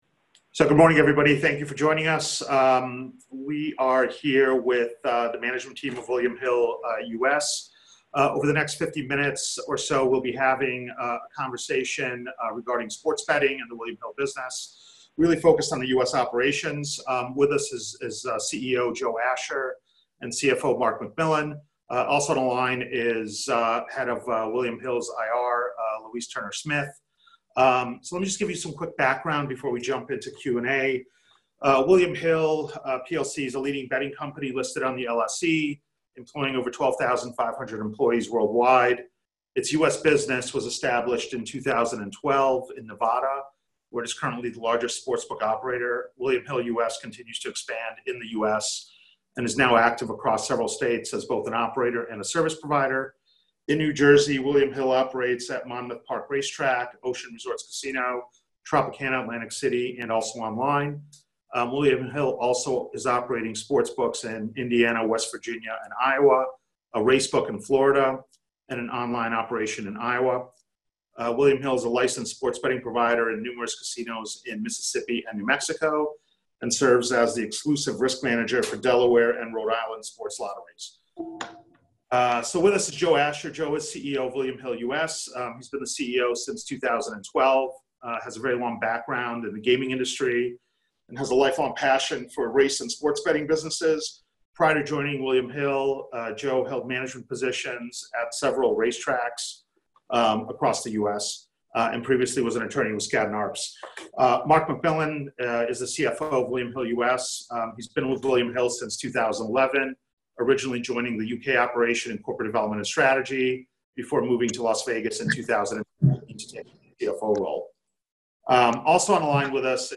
Fireside Chat